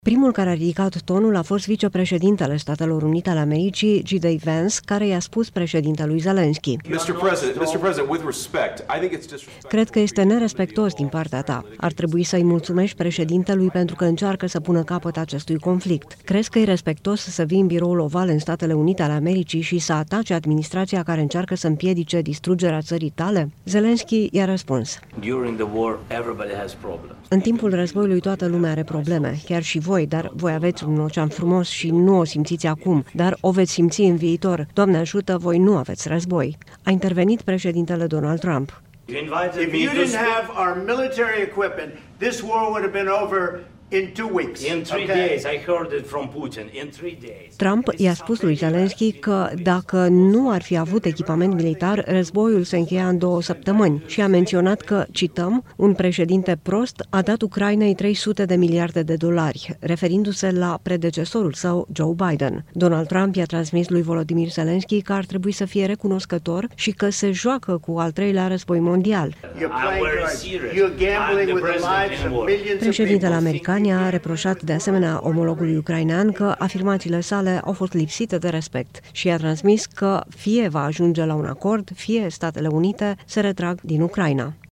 Schimburi de replici tensionate la Casa Albă.
Primul care a ridicat tonul a fost vicepreședintele SUA, JD Vance, care i-a spus președintelui Zelenski: